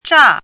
zhà
乍 [zhà]
zha4.mp3